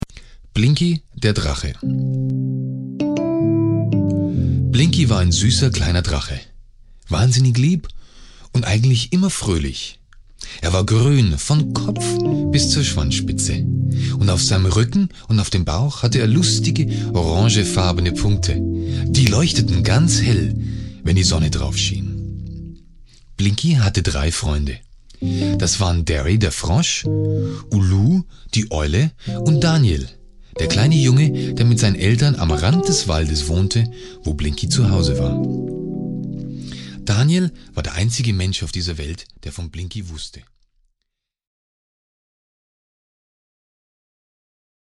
deutscher Sprecher
bayerisch
german voice over artist